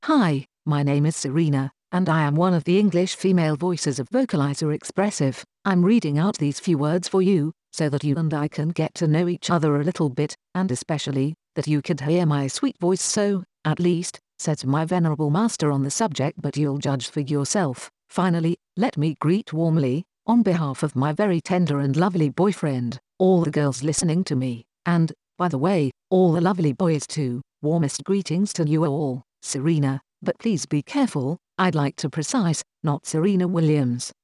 Texte de démonstration lu par Serena, voix féminine anglaise de Vocalizer Expressive
Écouter la démonstration d'Serena, voix féminine anglaise de Vocalizer Expressive